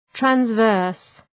{træns’vɜ:rs}
transverse.mp3